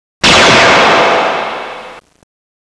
Photon blast - Soundatabase
Photon blast